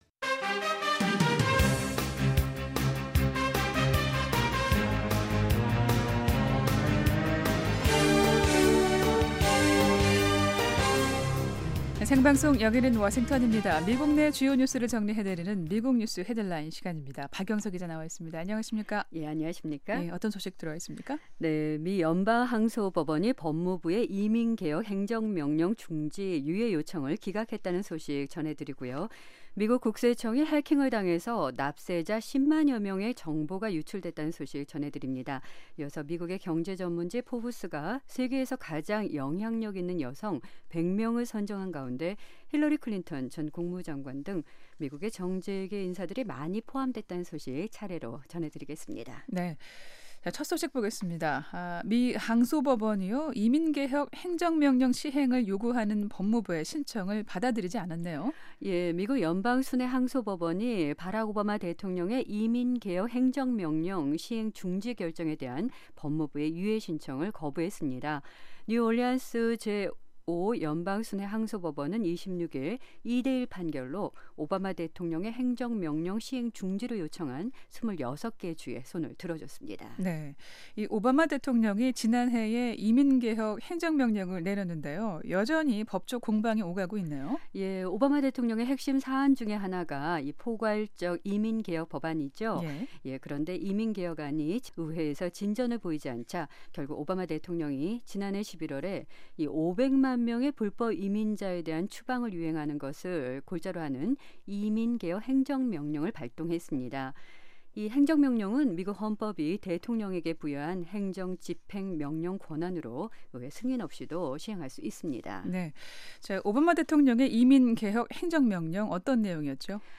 미국 내 주요 뉴스를 정리해 드리는 ‘미국 뉴스 헤드라인’입니다. 미 연방 항소법원이 이민개혁 행정명령 시행 중지 결정에 대한 법무부의 긴급 유예 요청을 기각했다는 소식 전해드립니다.